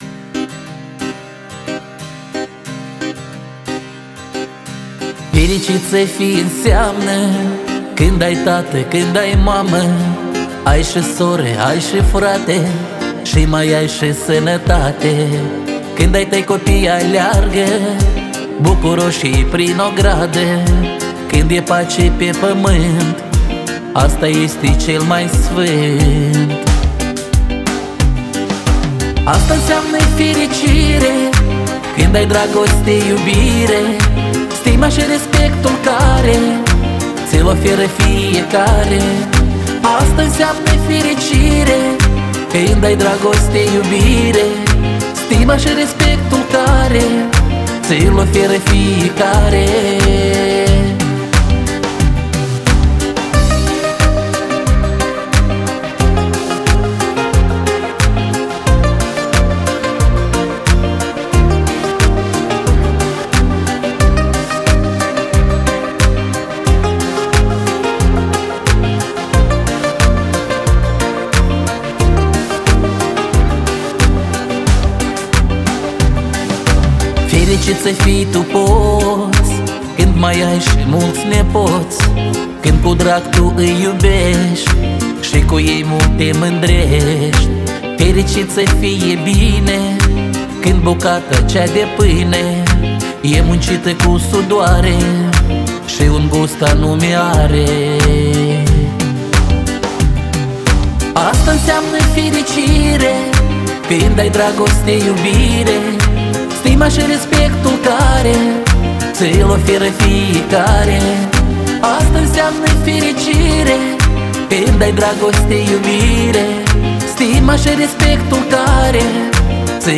Казахская